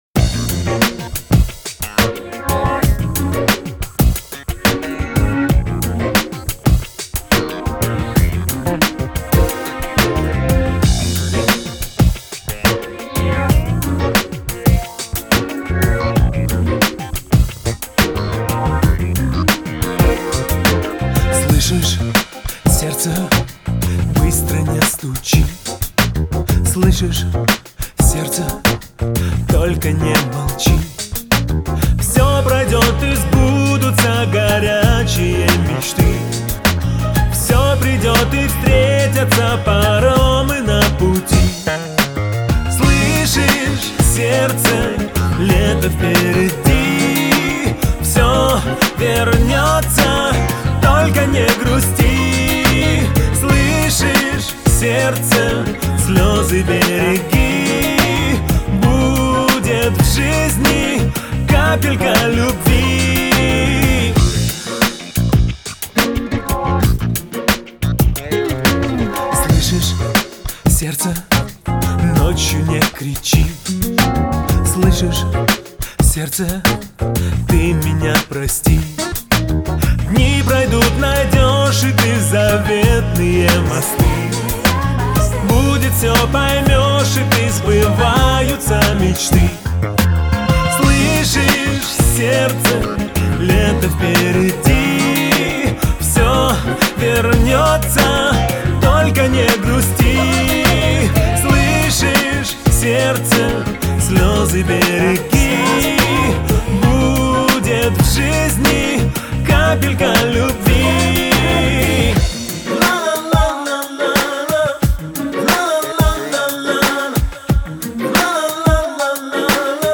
это трек в жанре поп с элементами этнической музыки